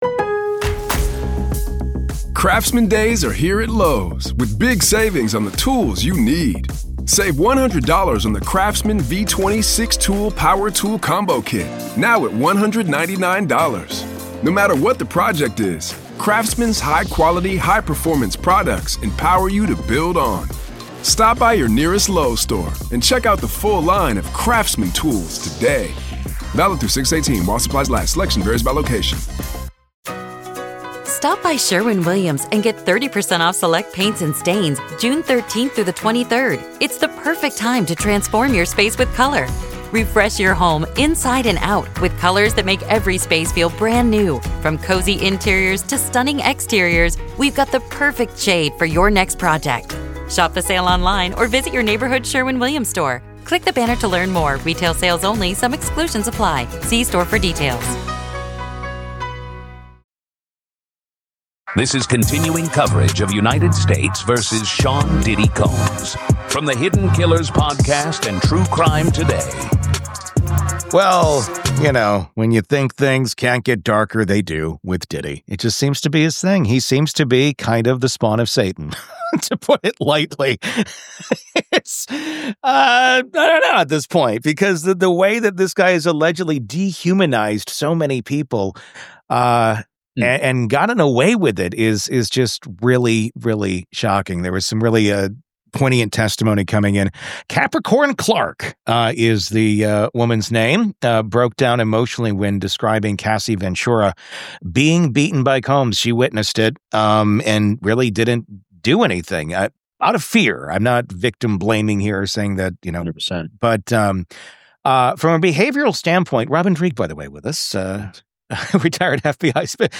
retired FBI Behavioral Analyst